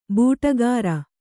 ♪ būṭagāra